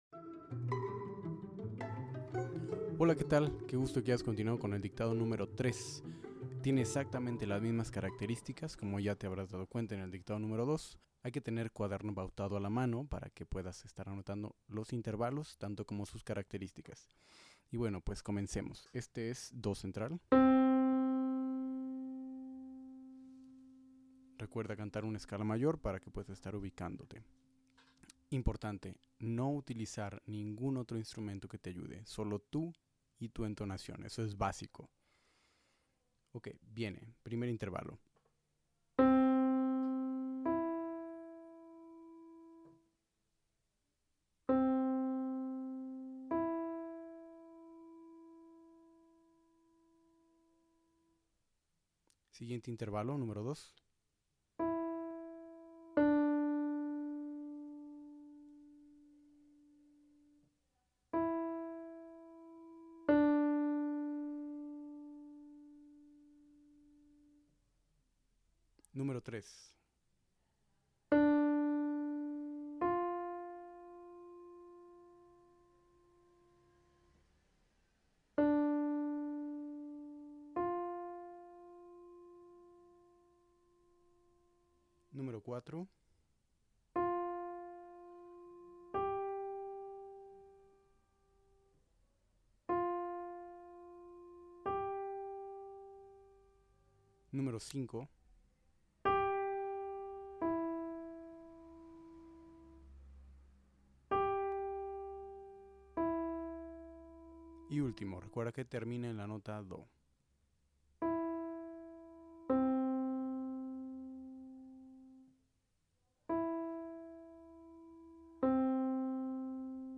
Dictado-de-intervalos-3.Nivel-básico-1.mp3